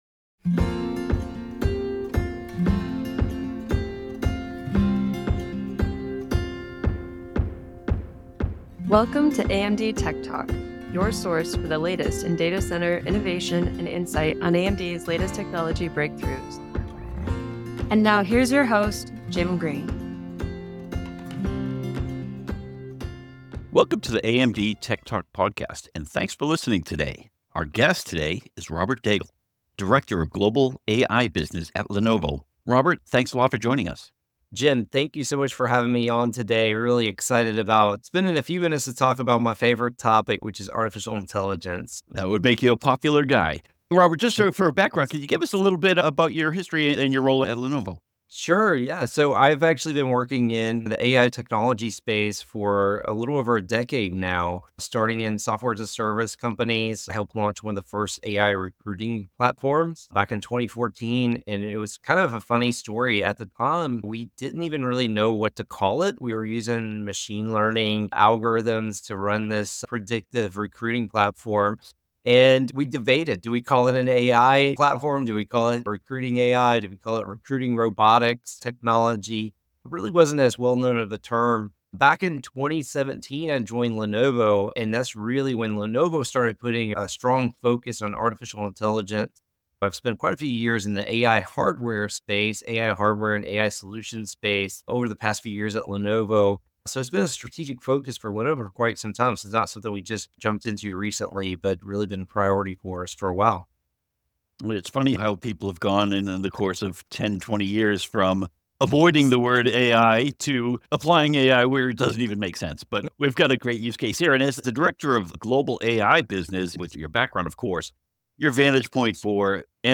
and featuring leading technologists from AMD and the industry, AMD TechTalk features discussion on servers, cloud computing, AI, HPC and more.